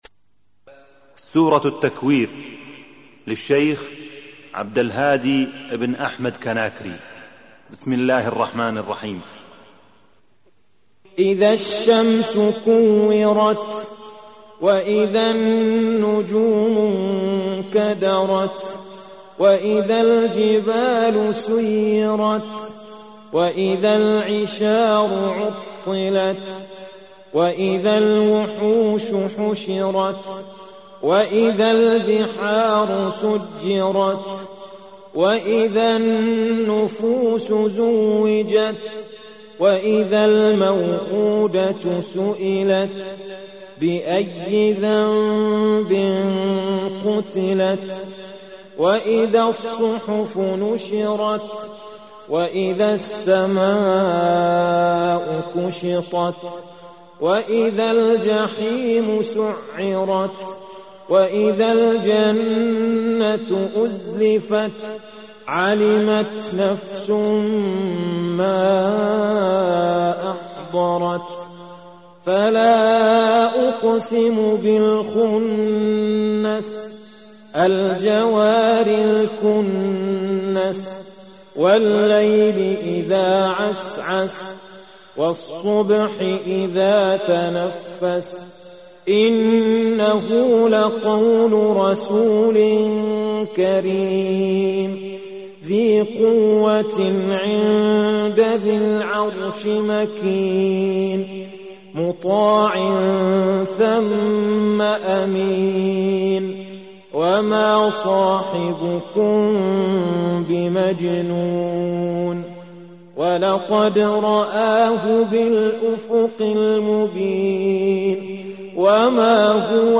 Tilawat Al-Quran AlKarim
(in the voices of famous Qaris of the world )
Qirat - Takweer.mp3